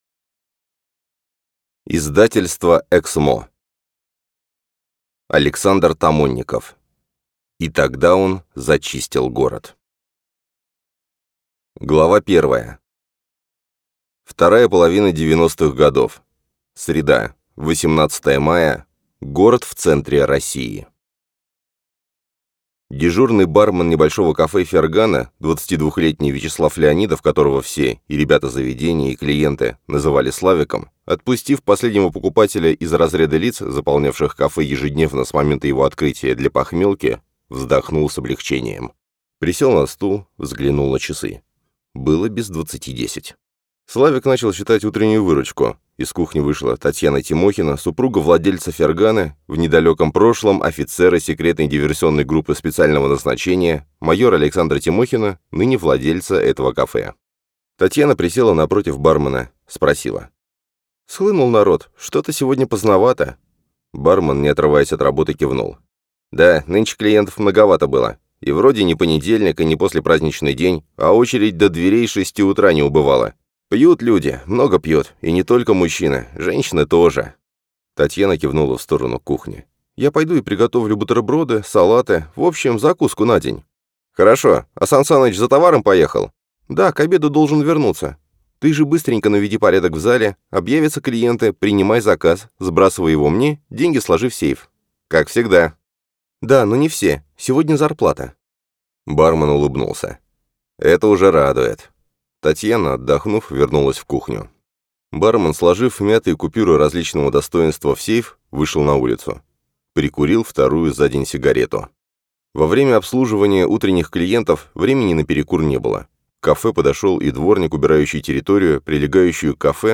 Аудиокнига И тогда он зачистил город | Библиотека аудиокниг